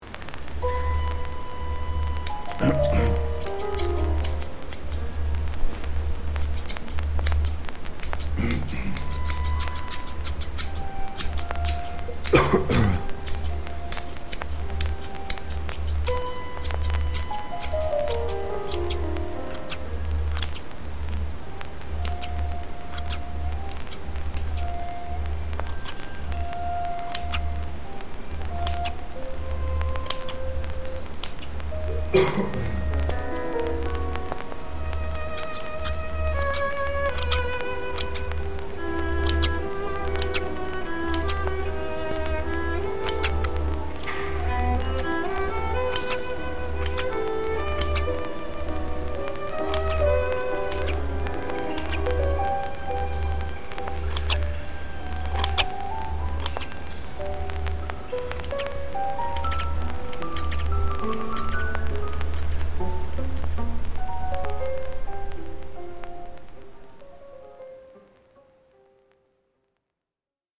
30 Enero 2009 Equipo: Edición en computadora Tema musical de fondo: Lorenz: Cecilia en Azul y Verde. interpretada por